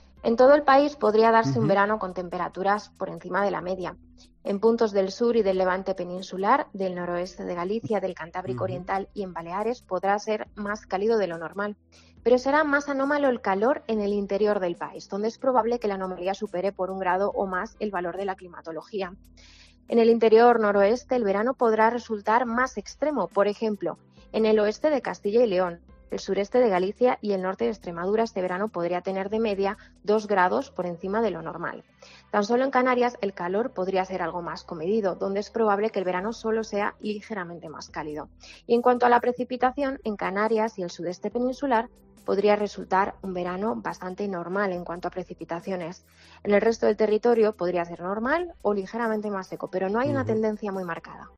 Una meteoróloga da las claves de cómo serán los próximos meses